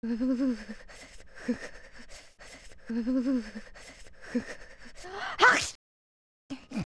OOT_YoungLink_SneezeFull.wav